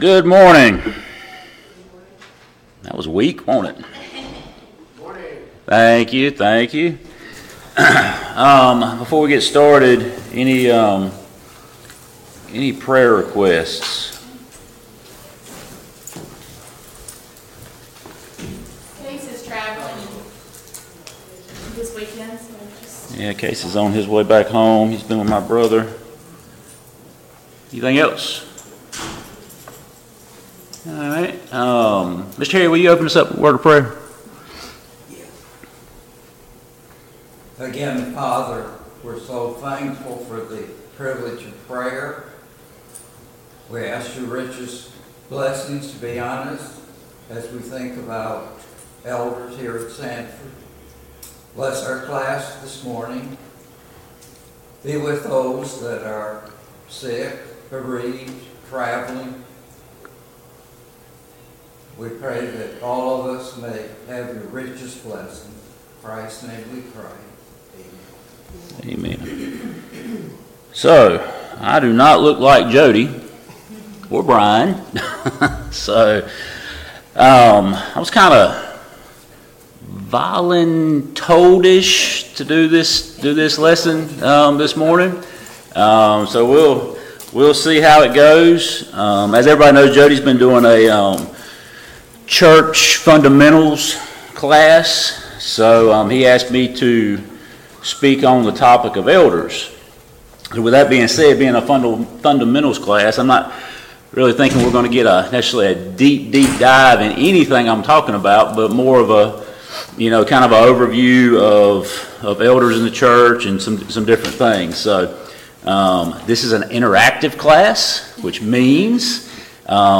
Sunday Morning Bible Class Topics: Elderships